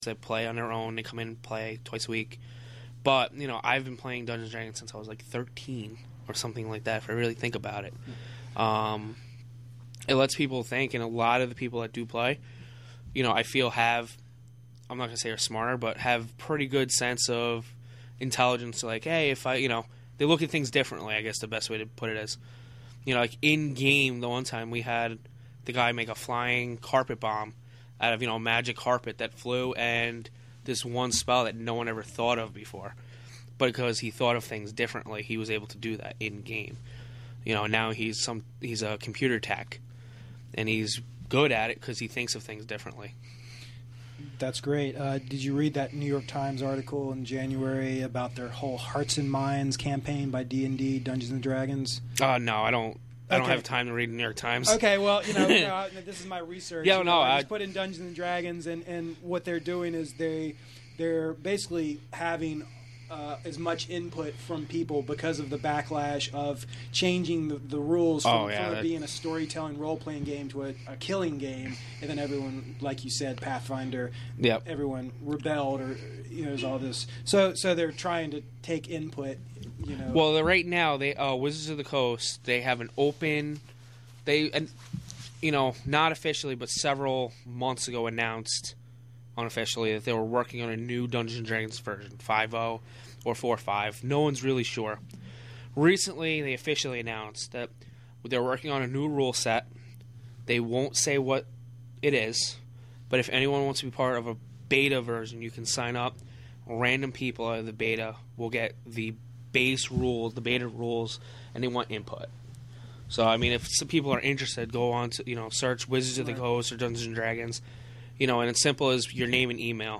Live from the Catskill Community Center with guest...